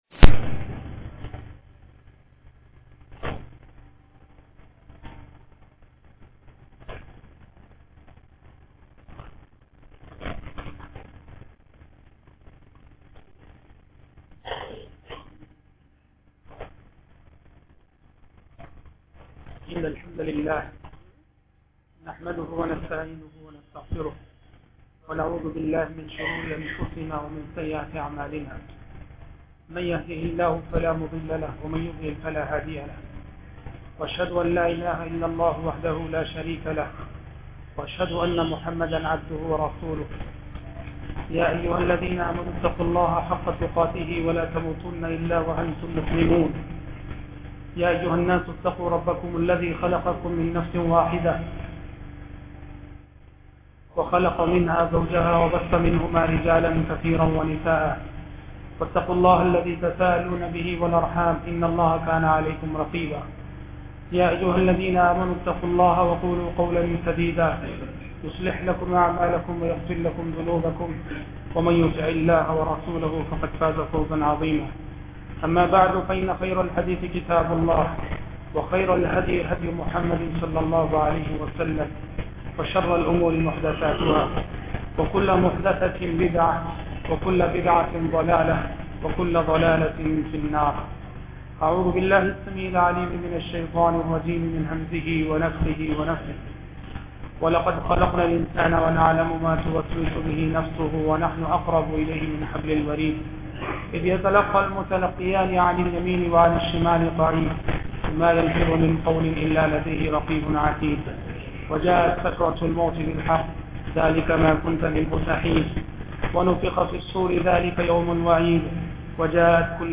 خطاب